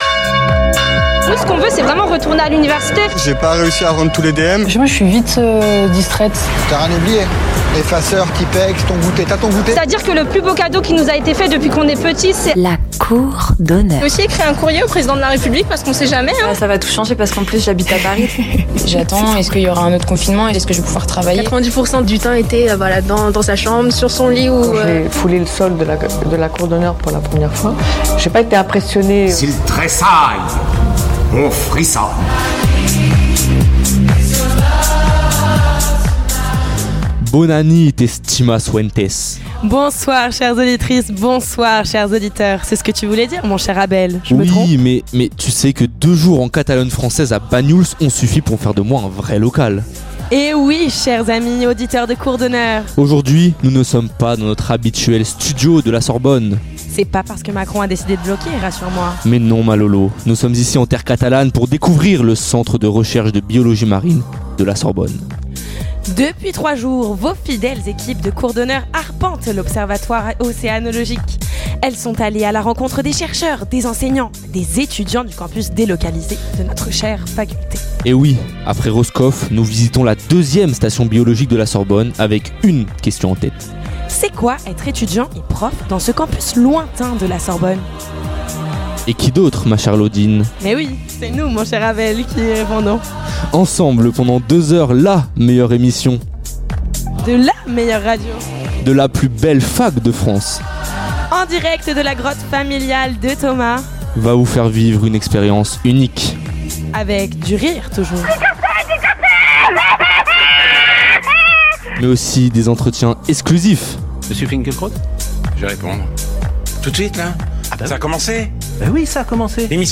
Emission spéciale à l'Observatoire Océanologique de Banyuls-sur-mer de Sorbonne Université